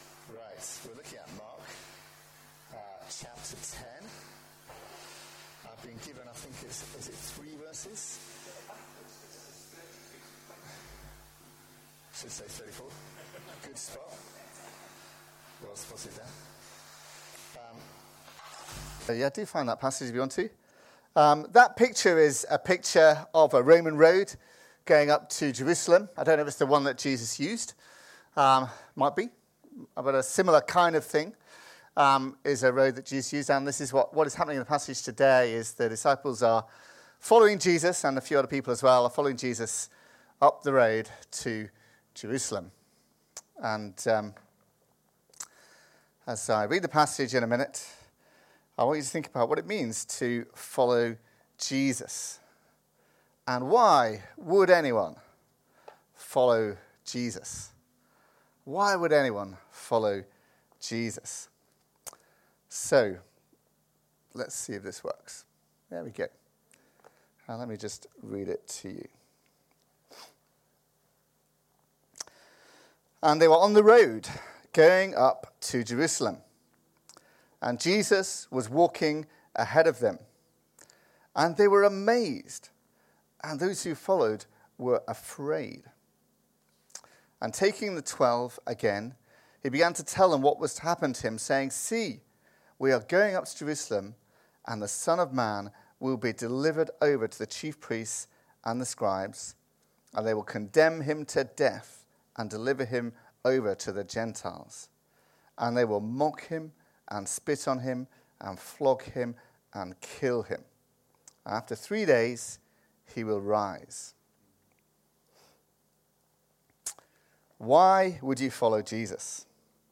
Download The Son Of Man | Sermons at Trinity Church